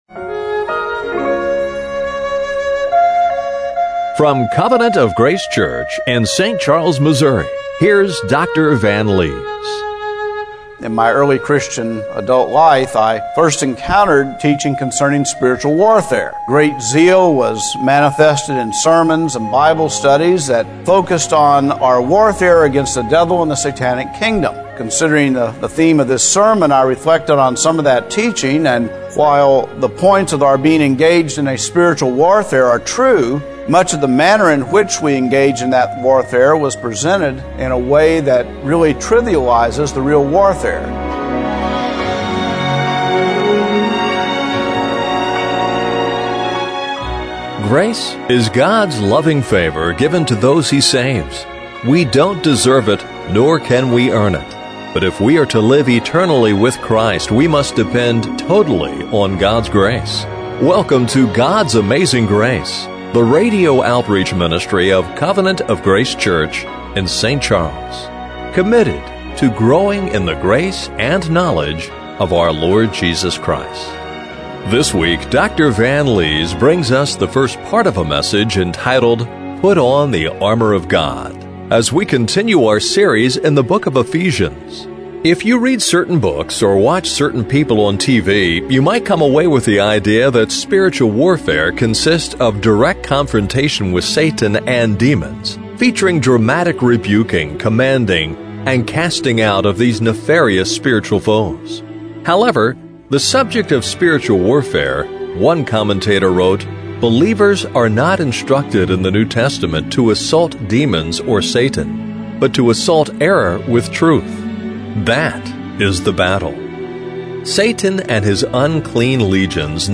Ephesians 6:10-20 Service Type: Radio Broadcast What is the whole armor of God